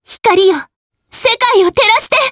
Frey-Vox_Skill5_jp.wav